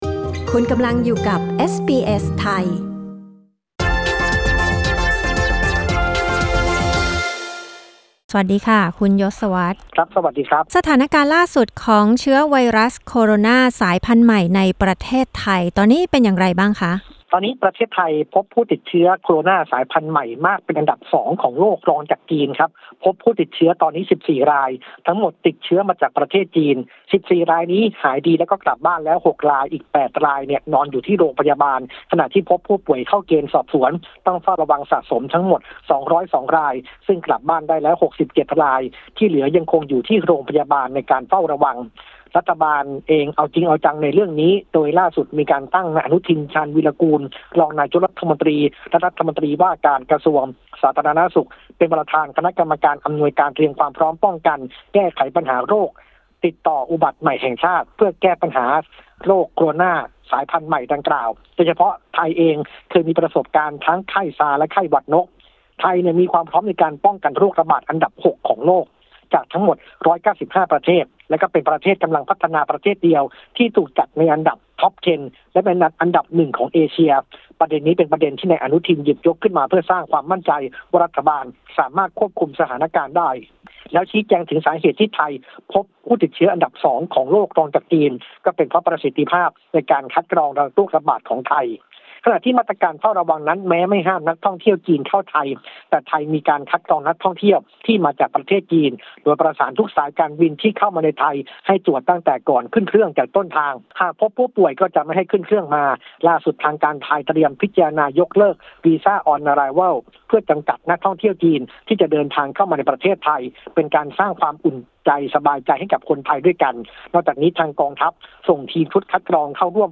กดปุ่ม 🔊 ที่ภาพด้านบนเพื่อฟังรายงานข่าว